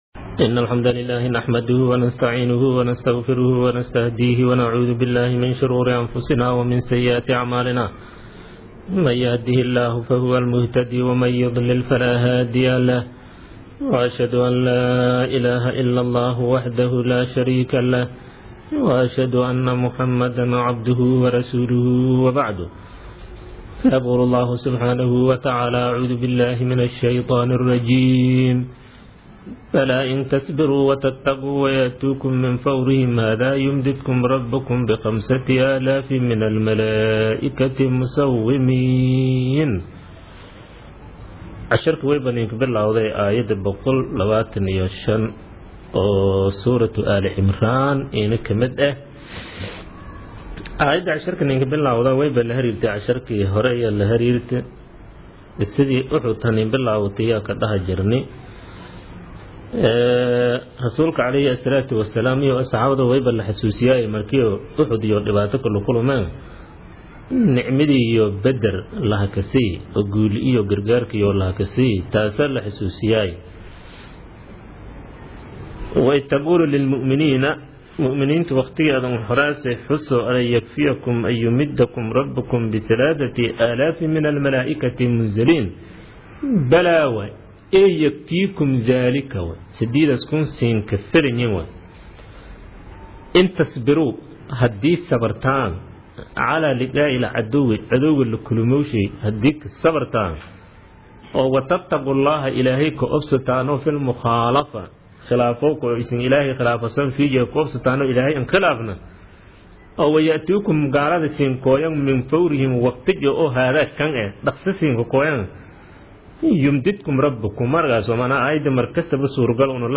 Casharka Tafsiirka Maay 51aad